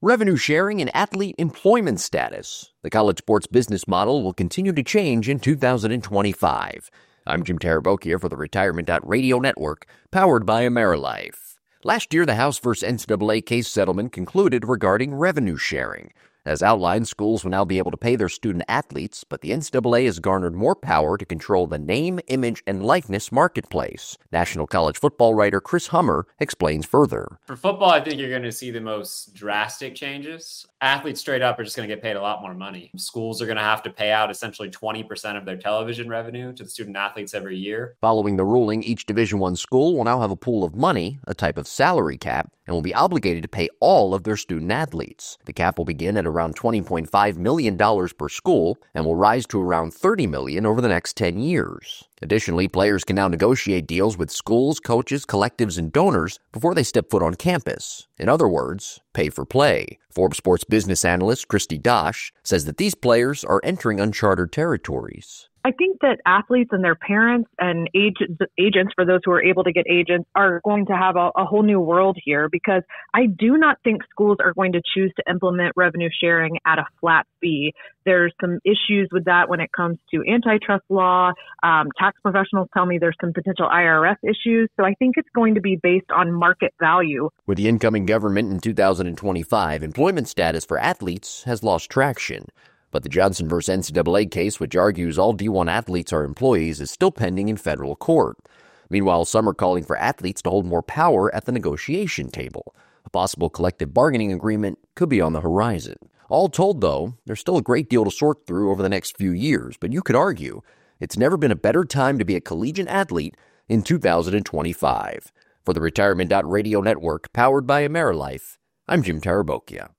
Bonus Interview: Collegiate Athletics Facing Major Changes in 2025